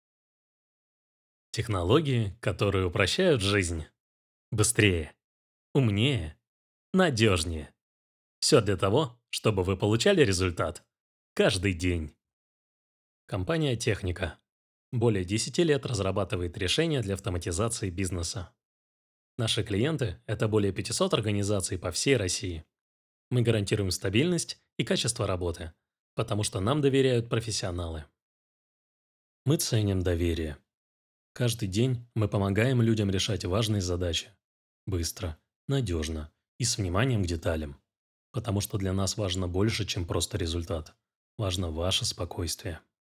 Сделать заказ Профессиональный мужской голос: чёткий, уверенный, с выразительным тембром.
Записываю в собственной студии с конденсаторным микрофоном и iRig Pro I/O. Готов к заказам в жанре рекламы, инфо-роликов, озвучки обучающих курсов и сторителлинга.